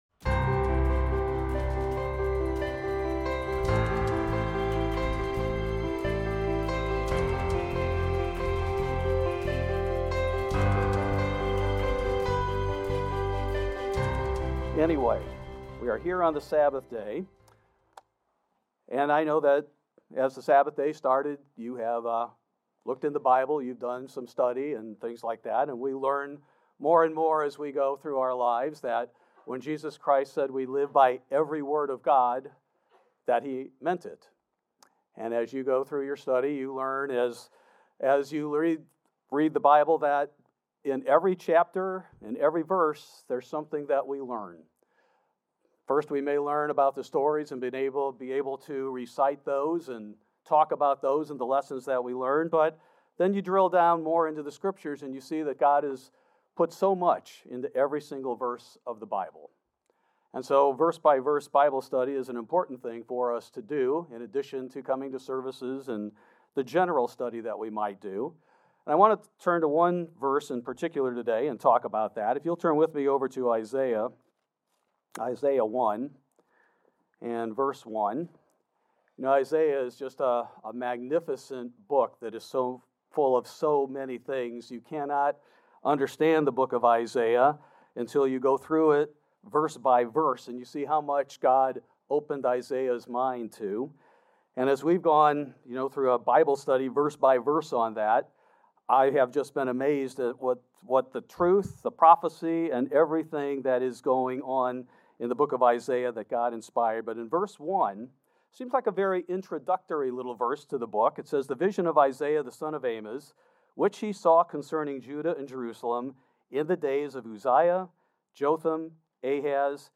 In this sermon we look at one of those kings and glean what we need to beware of.